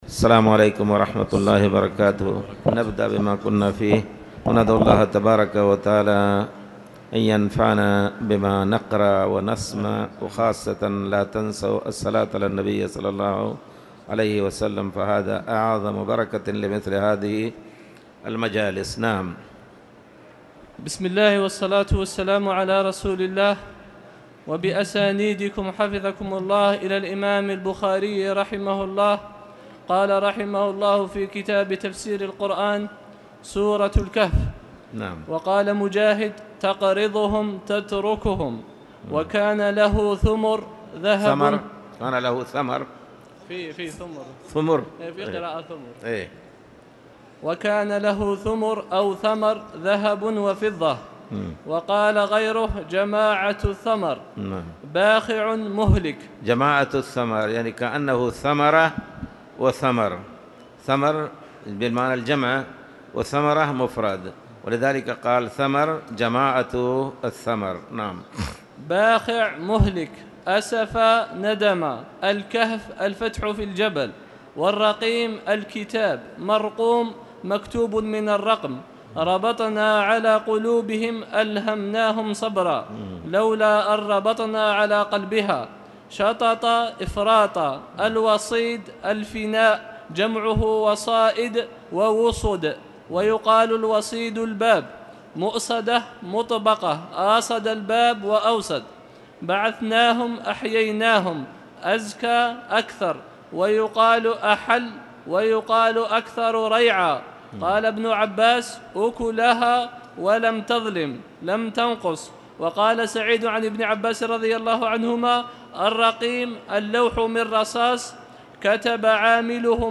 تاريخ النشر ٢١ محرم ١٤٣٨ هـ المكان: المسجد الحرام الشيخ